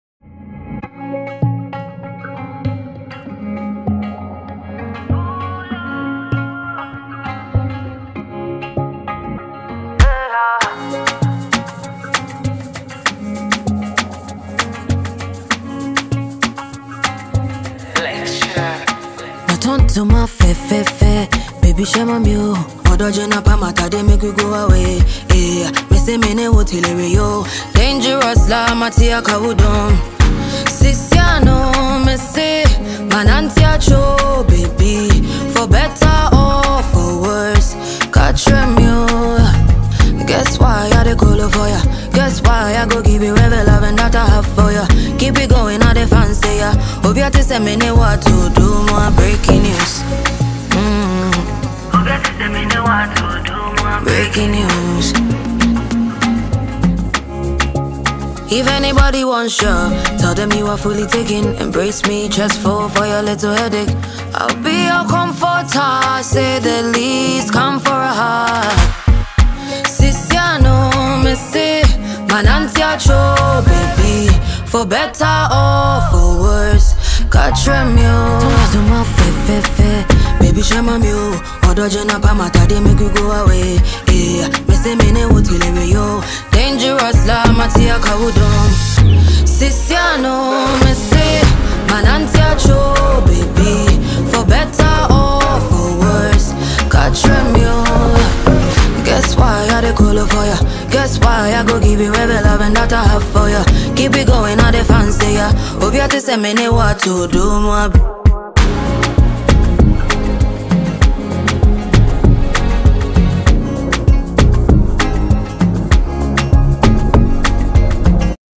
Top-notch Ghanaian female singer and songwriter
catchy single